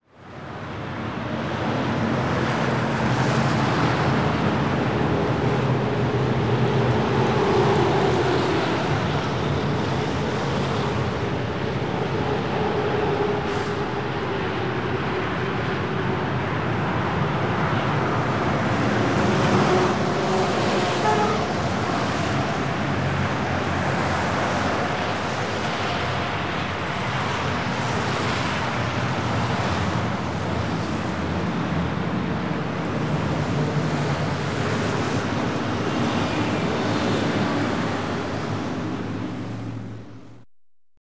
Автомобили мчатся по дорогам, снуют туда и сюда